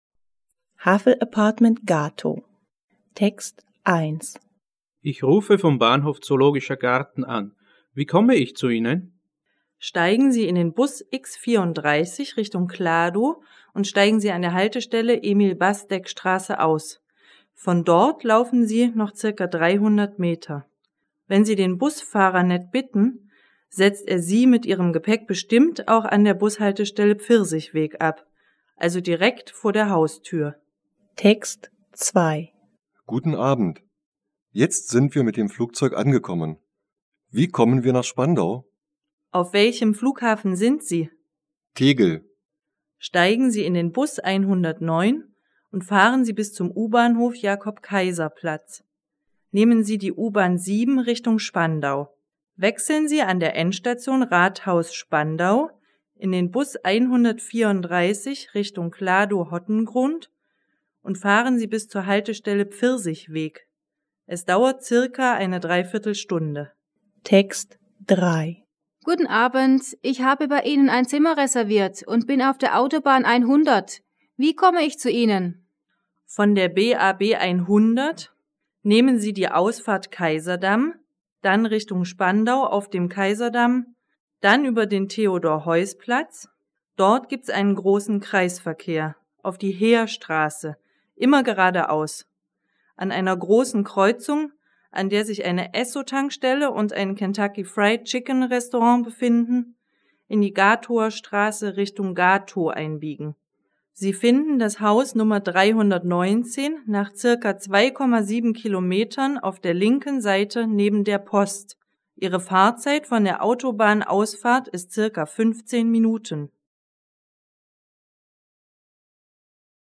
A CD-melléklet a hallott szöveg értését mérő feladatok hanganyagát tartalmazza, amelyet anyanyelvi beszélők közreműködésével készítettünk el.